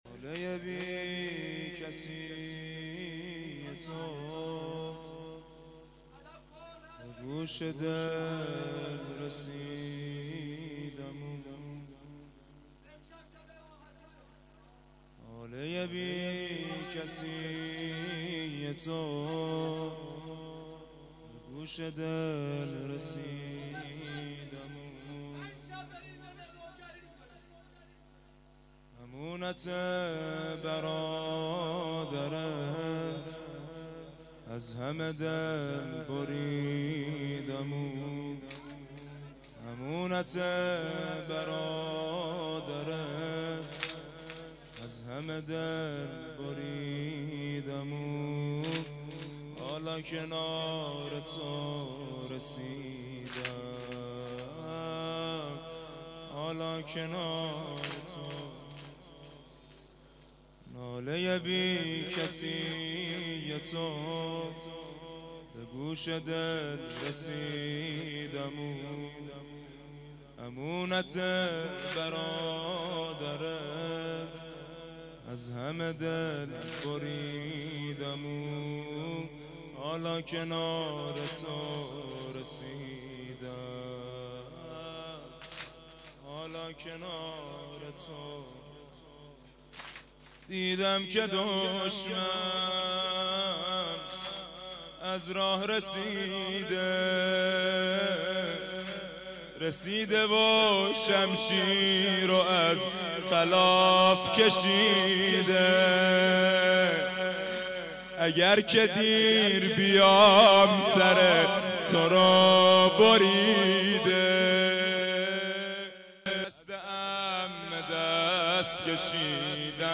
واحد شب پنجم محرم1391